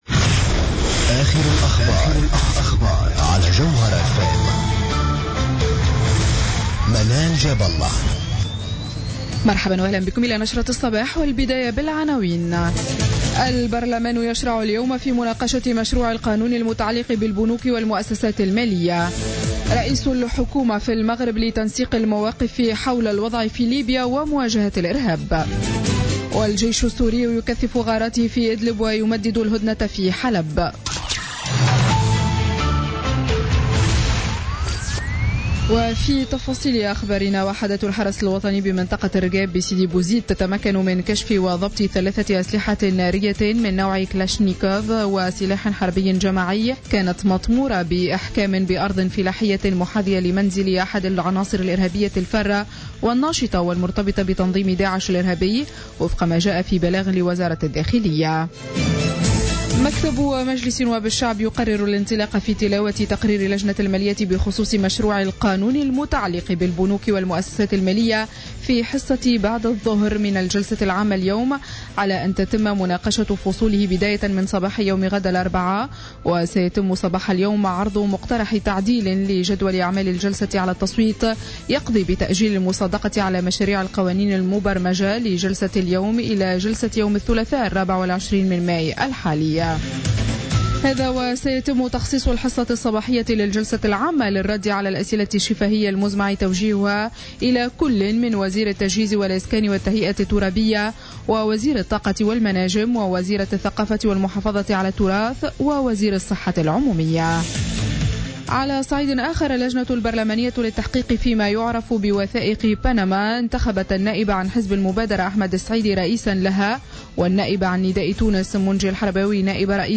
نشرة أخبار السابعة صباحا ليوم الثلاثاء 10 ماي 2016